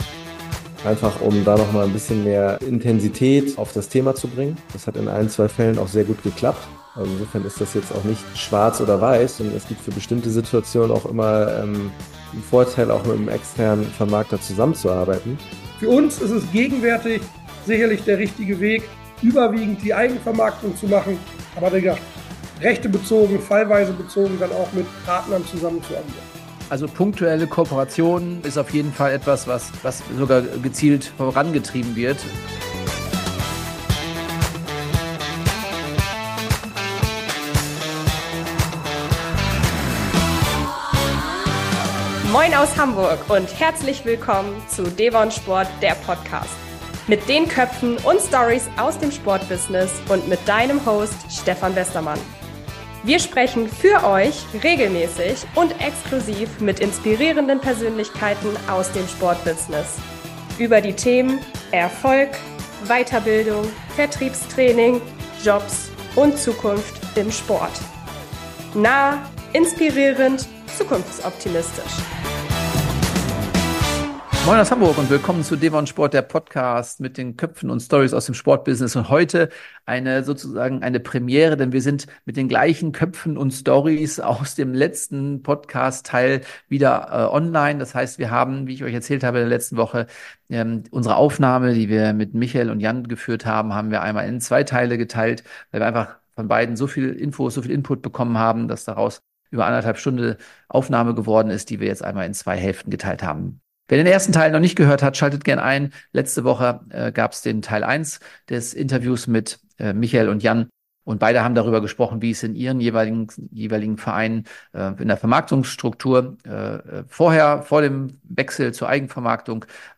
Beide Gesprächspartner haben seit 2023 die Eigenvermarktung ihrer Vereine gestartet und kennen sich bestens mit allen Chancen und Herausforderungen auf diesem Weg aus.